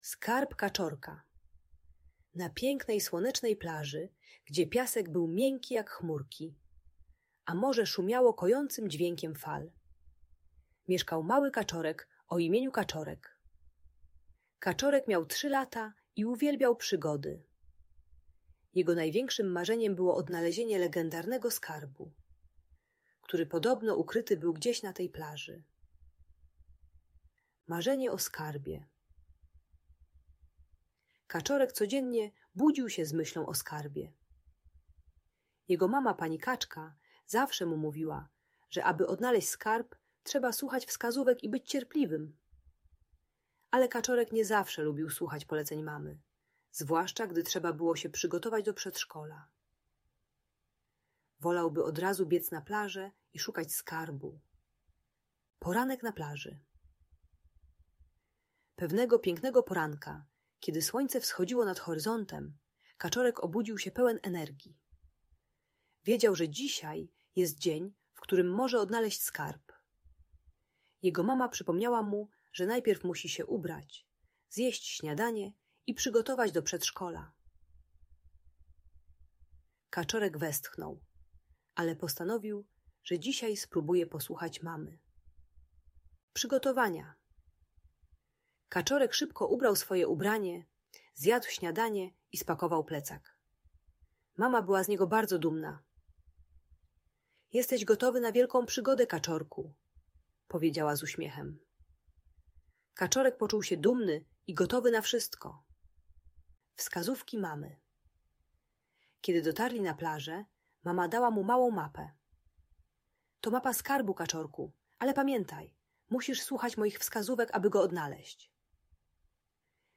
Historia Skarbu Kaczorka - Audiobajka